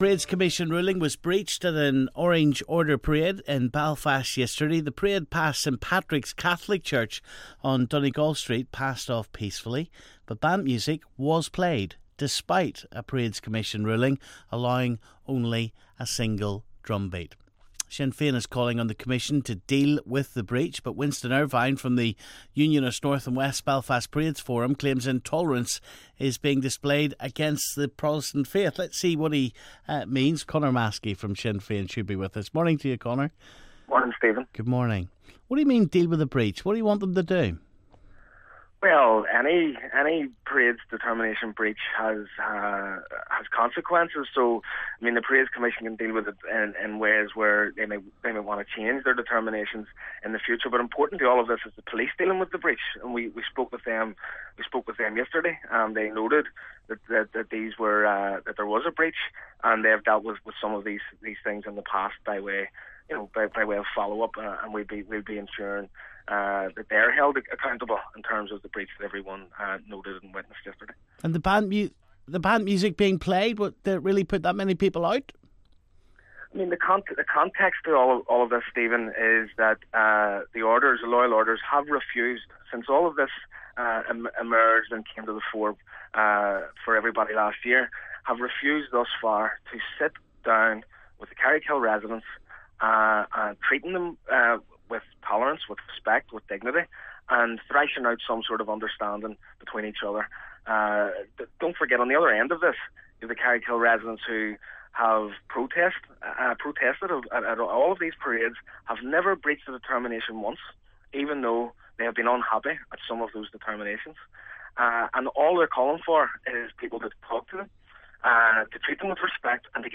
debate a Loyalist band's breach of a Parades Commission ruling yesterday